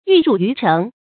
玉汝于成的讀法